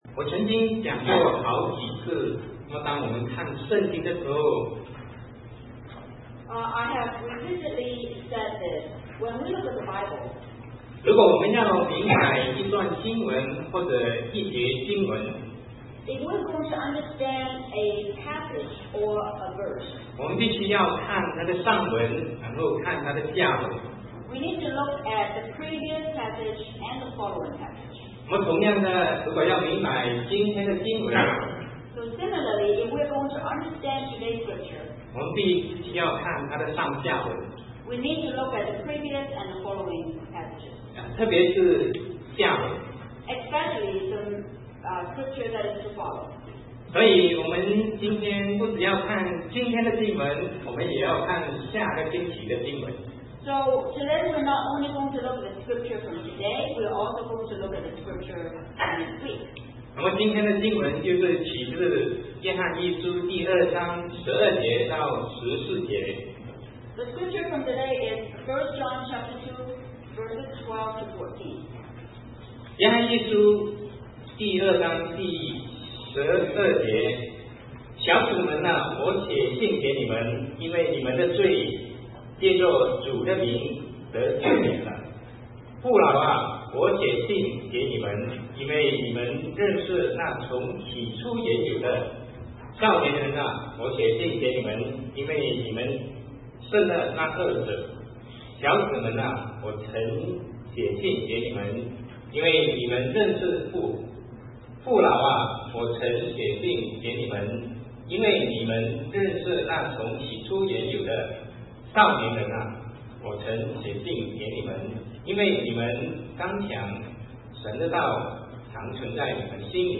Sermon 2009-09-13 Our Identity and Responsibility